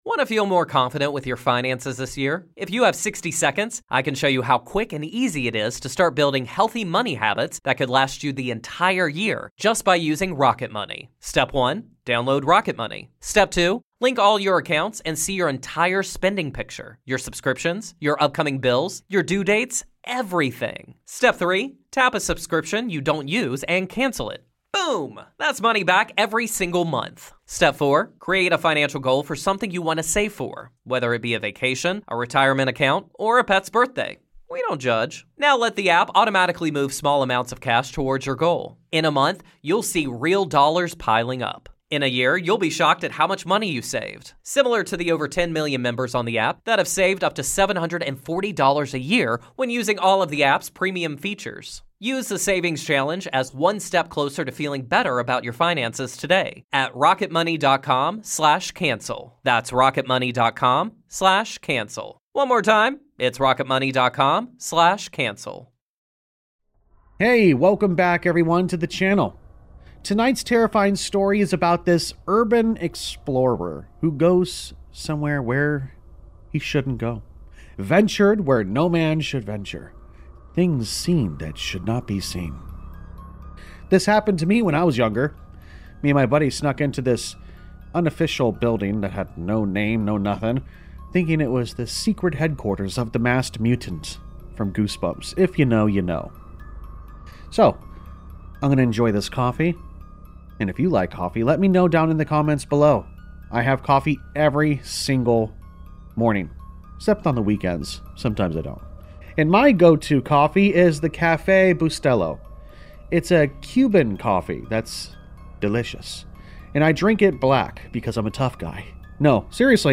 Tonight’s Creepypasta delivers gripping tension and atmospheric dread as this tale unravels into one of the most unsettling True Scary Stories you’ll hear.